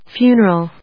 音節fu・ner・al 発音記号・読み方
/fjúːn(ə)rəl(米国英語), ˈfju:nɜ:ʌl(英国英語)/